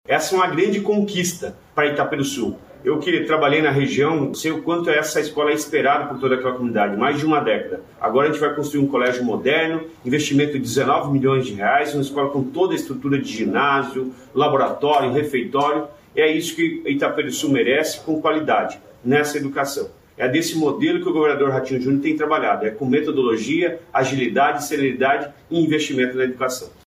Sonora do secretário da Educação, Roni Miranda, sobre a construção da nova escola em Itaperuçu